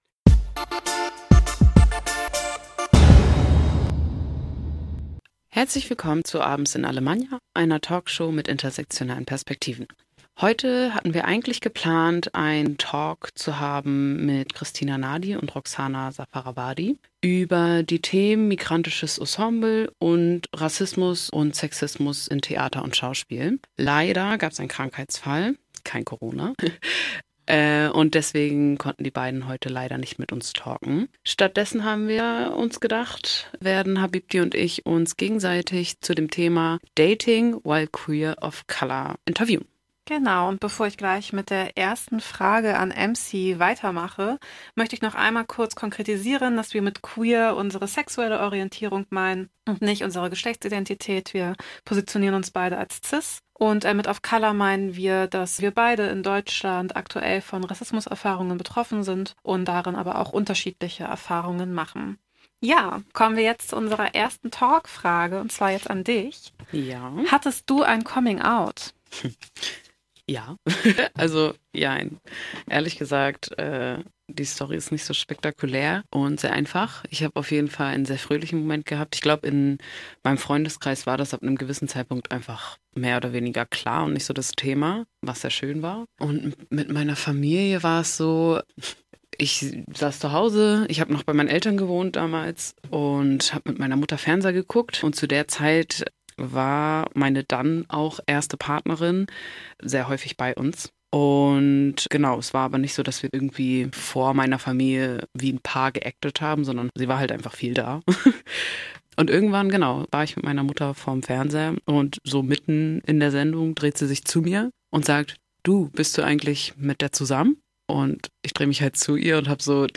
Eine Talkshow mit intersektionalen Perspektiven